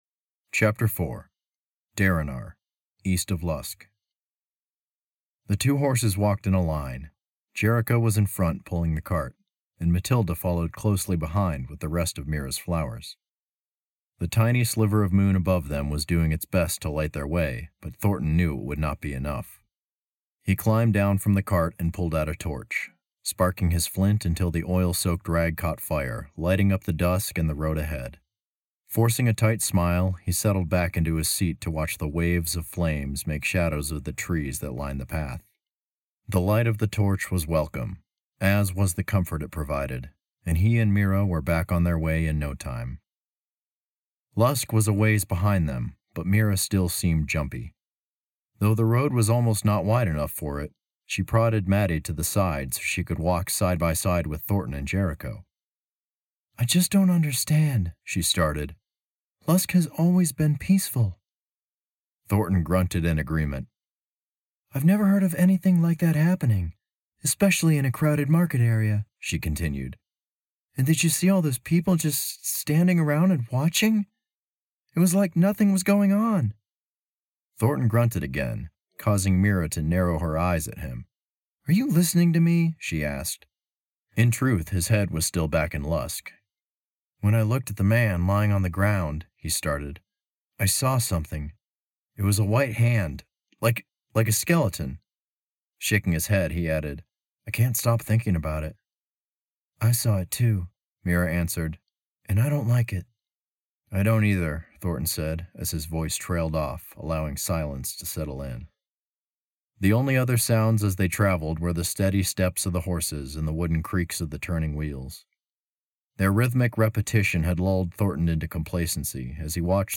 Audiobook update #1
To make a long story short, my editor is going through each of my recorded 56 chapters (plus a prologue) and making them sound like a REAL, ACTUAL AUDIO BOOK!
It’s a 5 minute cut, but it will let you know exactly what kind of (a) audio quality, and (b) voice acting you can expect to hear from the finished product.